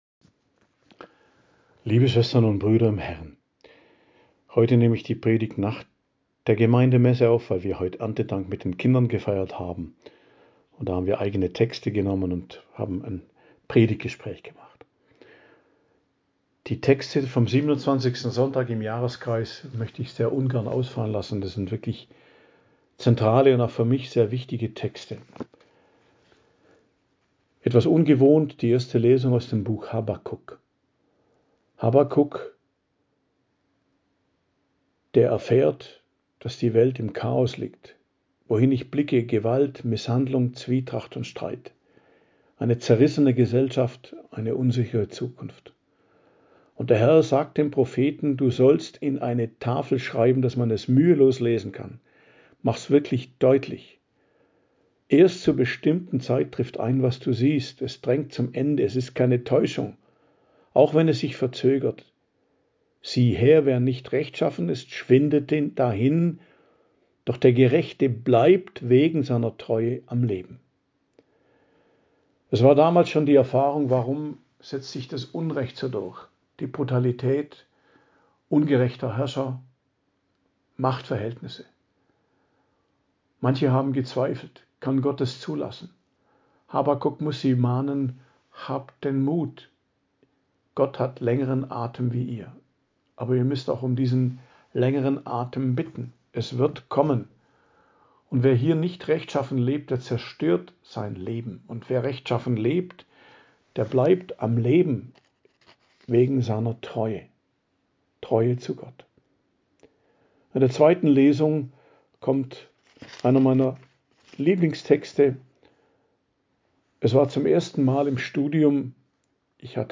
Predigt zum 27. Sonntag i.J., 5.10.2025 ~ Geistliches Zentrum Kloster Heiligkreuztal Podcast